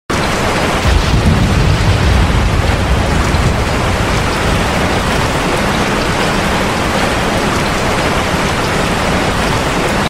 Enjoy the Sound of Thunder sound effects free download
Enjoy the Sound of Thunder During Rain | ASMR
From gentle ASMR rain sounds to the heavy rain in peaceful countryside — every second brings deep calm.